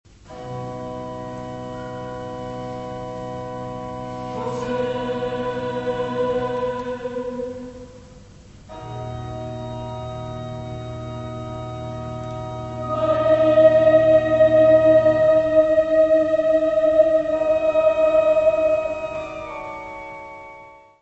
: stereo; 12 cm
Music Category/Genre:  Classical Music
Lento avec solennité; Joseph!